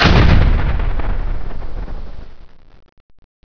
rocket_hit.wav